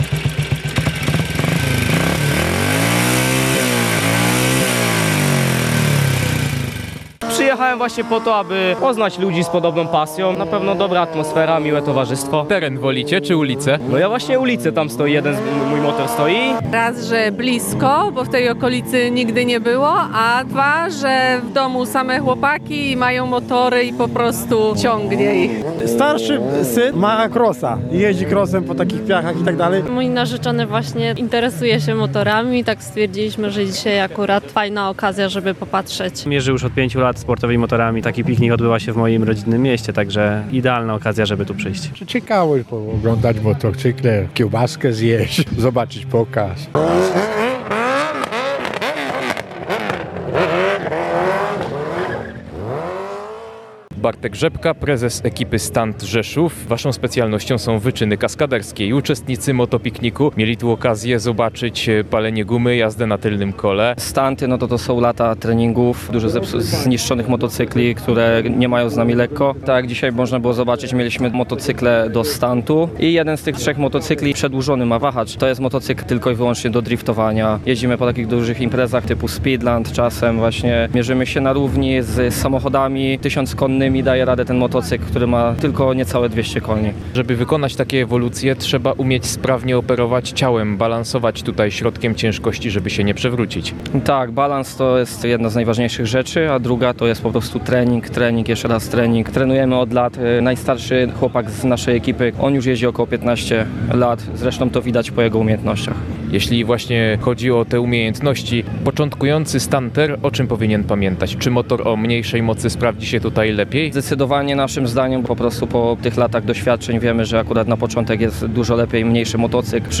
Możliwość podziwiania motocykli i quadów, chęć obejrzenia wyczynów stu tonowych z udziałem kaskaderów z Rzeszowa, czy nawiązanie nowych znajomości – między innymi te powody wymieniali mieszkańcy Nowej Dęby i okolic, którzy przyjechali na pierwszy w historii miasta Motopiknik.